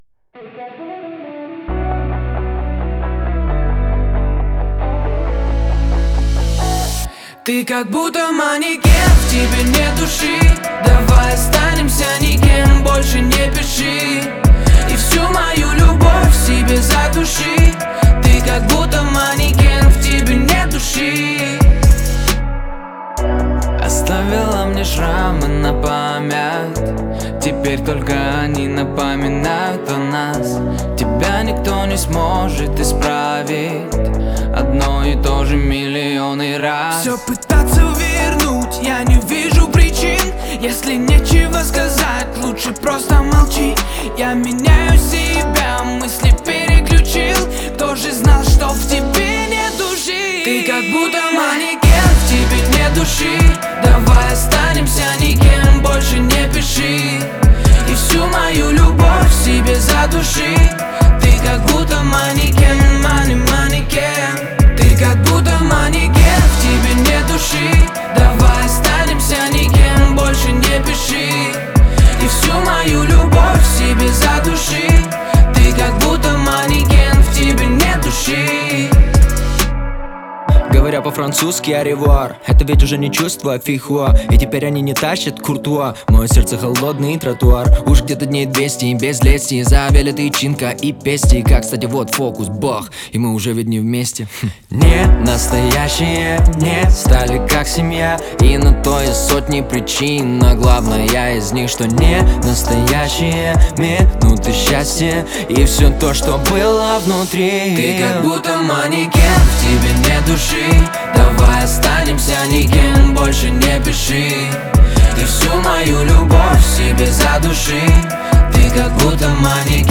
это яркая и запоминающаяся композиция в жанре поп-рэп